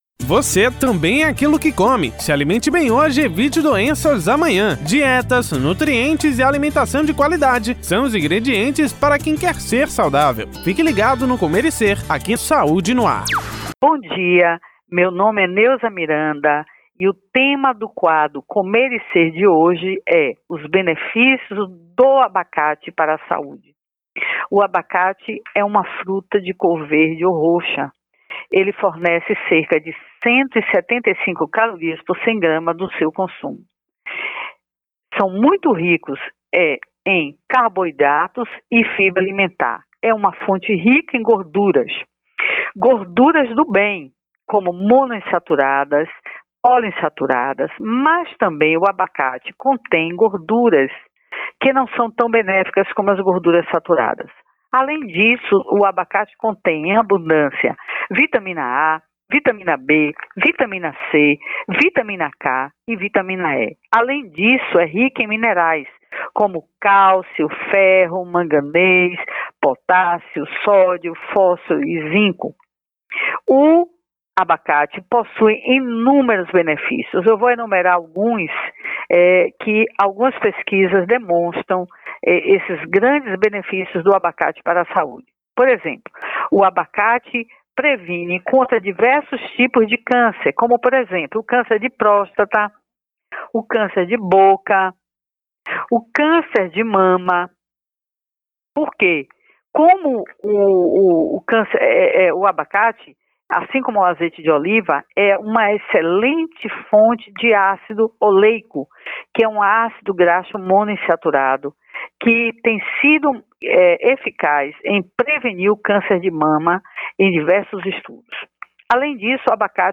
No quadro Comer e Ser que vai ao ar toda segunda-feira no programa Saúde no Ar na Rádio Excelsior Am 840